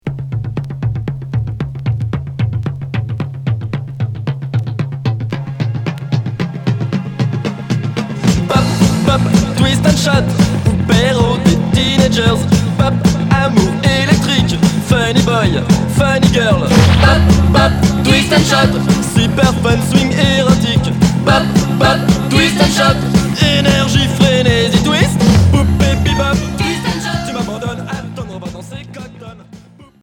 Punk rock new wave